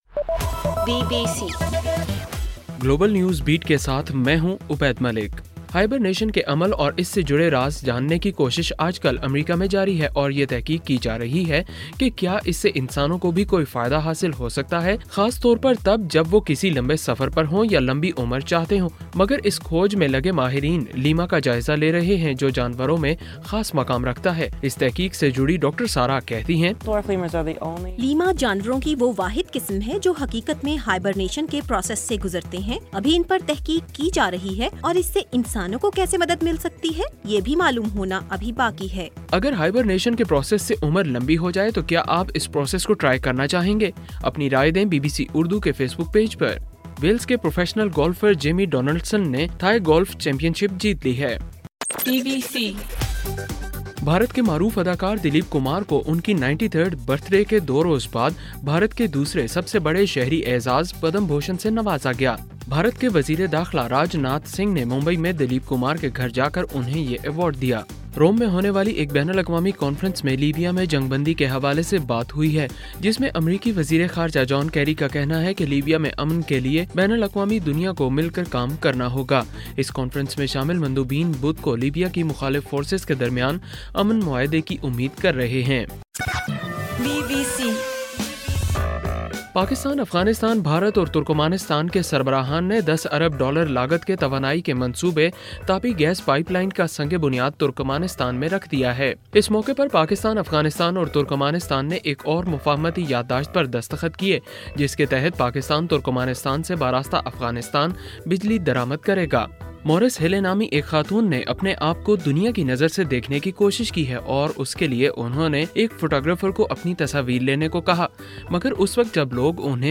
دسمبر 13: رات 11 بجے کا گلوبل نیوز بیٹ بُلیٹن